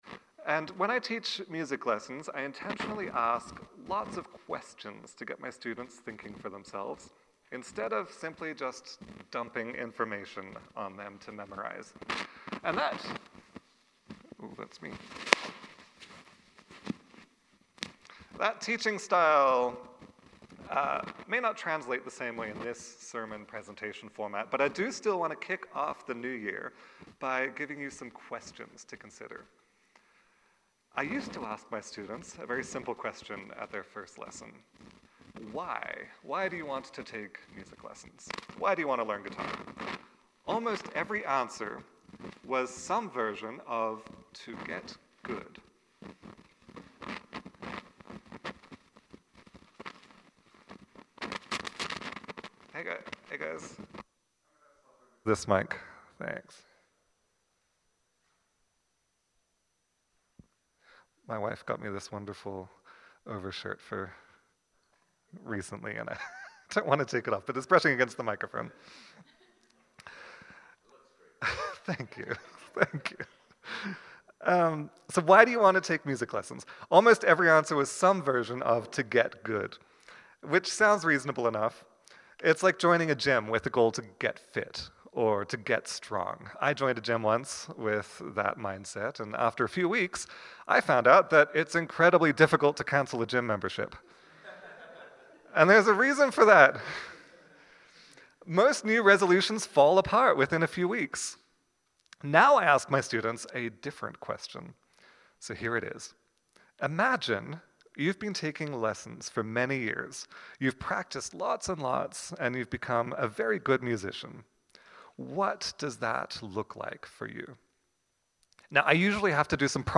Sermons | Clairmont Community Church